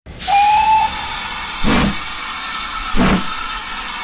Play Trian Whistle Loud - SoundBoardGuy
Play, download and share Trian Whistle Loud original sound button!!!!